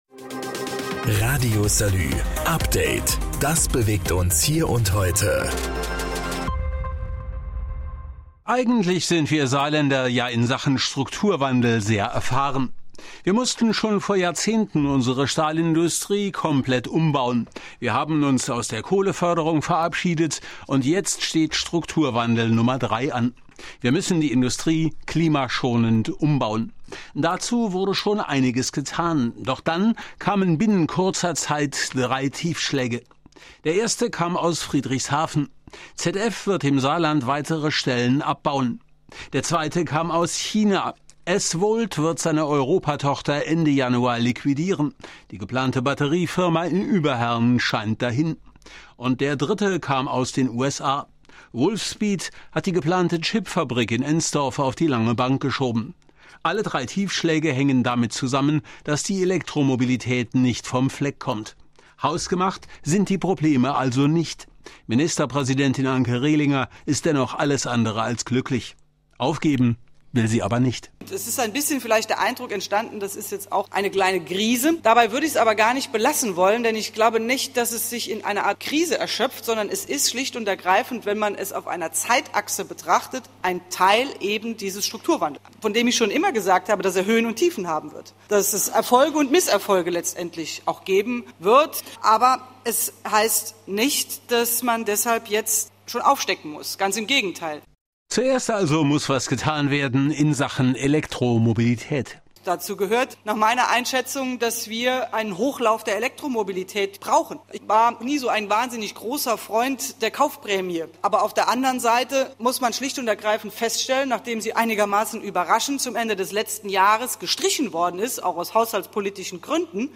Und er hat einige interessante Stimmen eingefangen Mehr